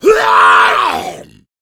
z_attack_8.ogg